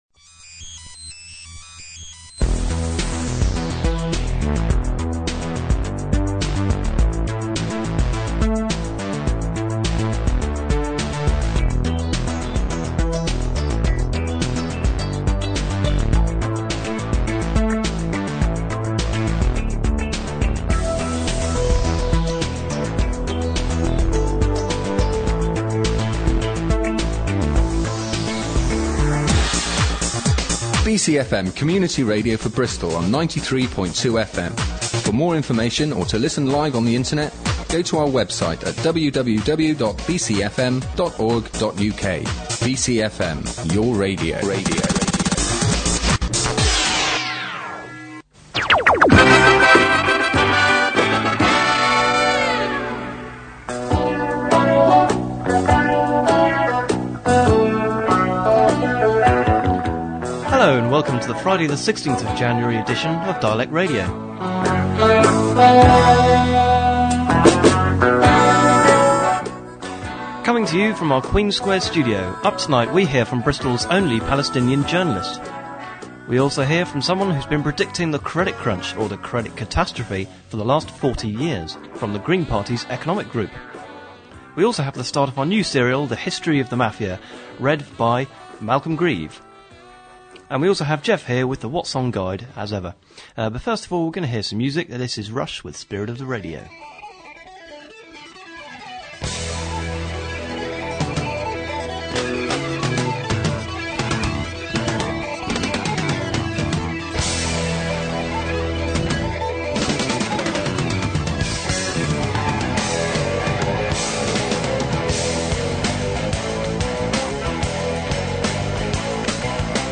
Our main activity is our weekly current affairs and arts magazine programme Dialect, which is recorded at our Queen's Square studios and posted for download every weekend.